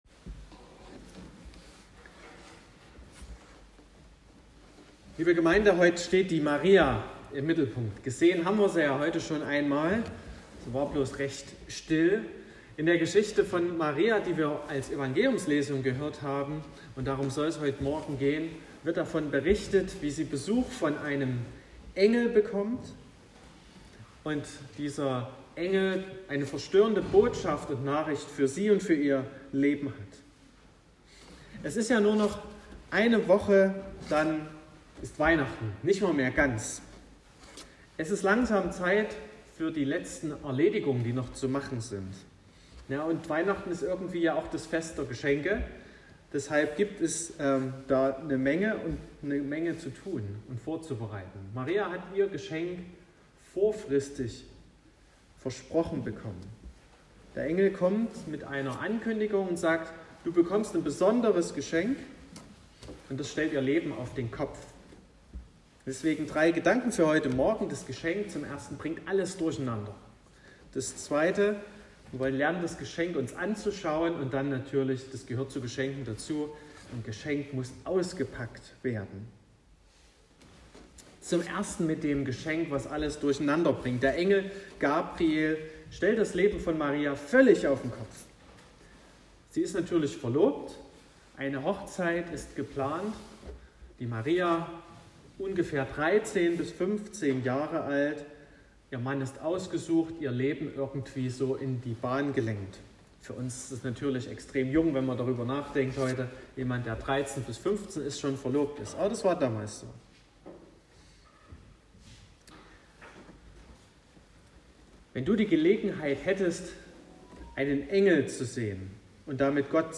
19.12.2021 – Gottesdienst
Predigt und Aufzeichnungen